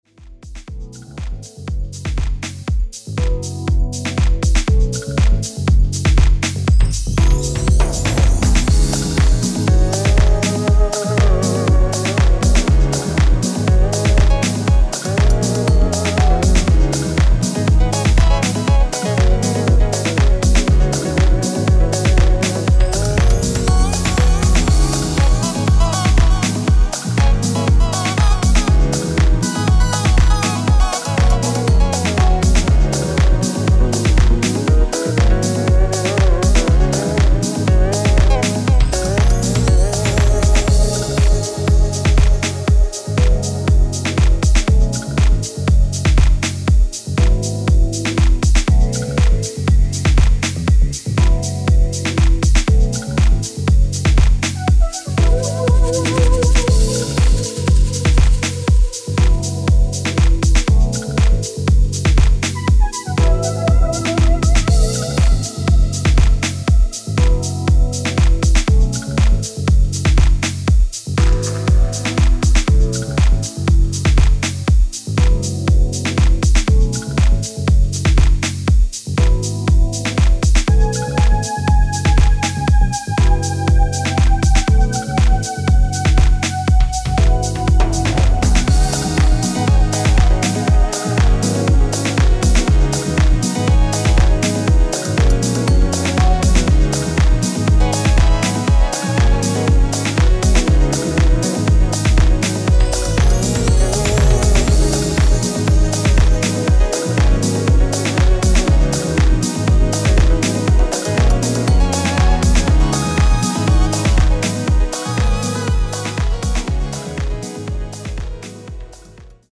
スペーシーなシンセ・ワークを効かせながら温もりのあるフュージョン・ハウスを繰り広げています。